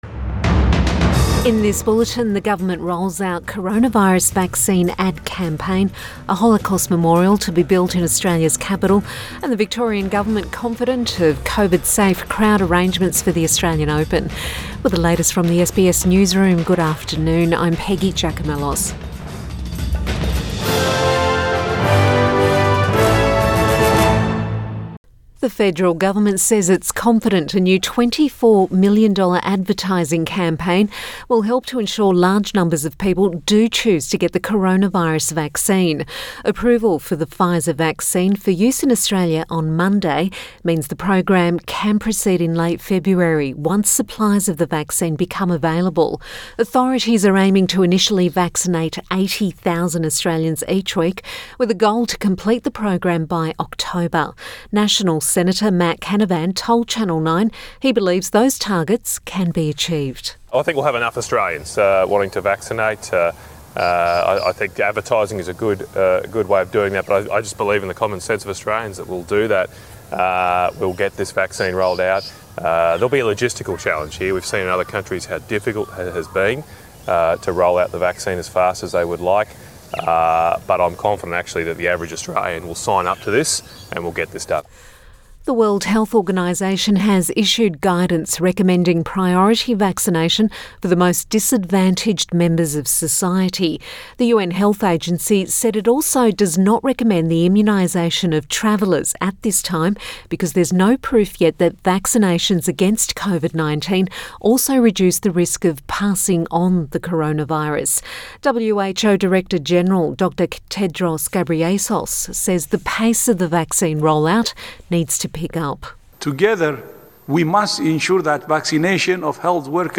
Midday bulletin 27 January 2021